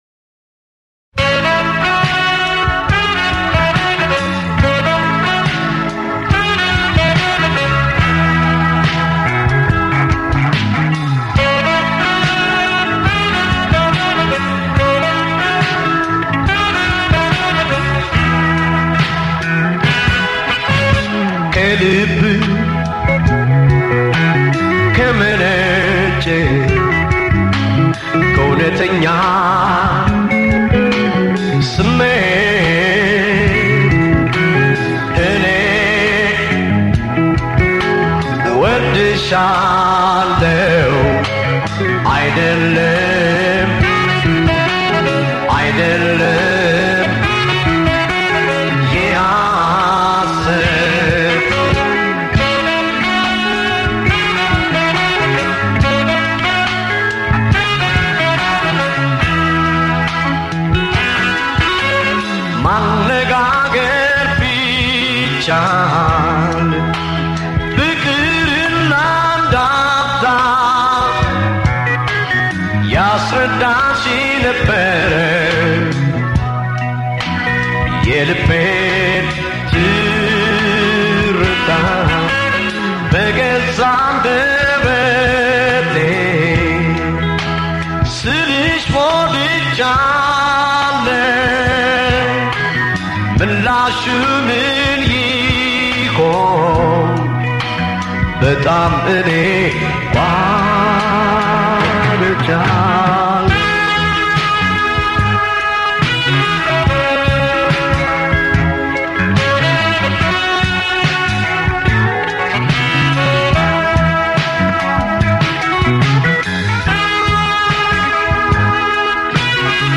Repeat all over again at a faster pace